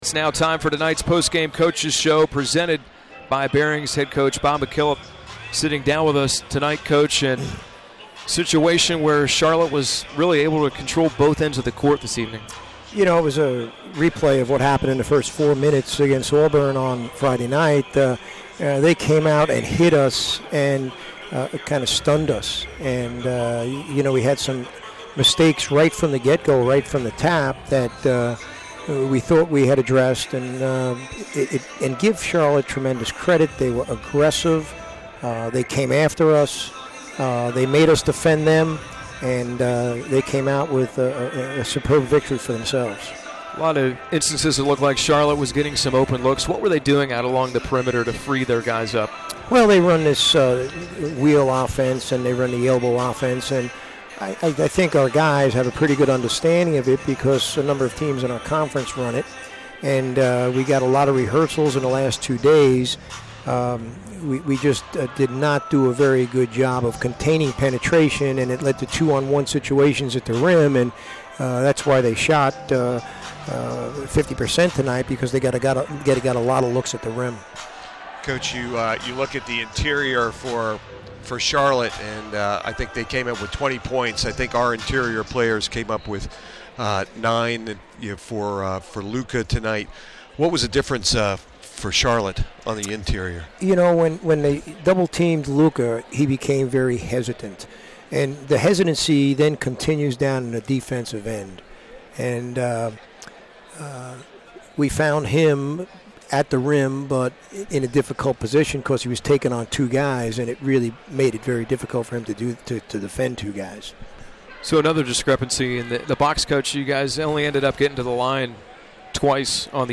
McKillop Postgame Interview
McKillop Postgame Charlotte.mp3